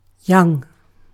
Ääntäminen
Vaihtoehtoiset kirjoitusmuodot (vanhentunut) younge Synonyymit immature junior juvenile youthful underdeveloped undeveloped new small brood nascent Ääntäminen : IPA : /jʌŋ/ US : IPA : [jʌŋ] UK : IPA : [jʌŋɡ]